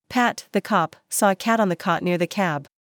TONGUE TWISTER 早口言葉
/æ/ Tongue Twister | American English
Tongue-Twister-ǽ.mp3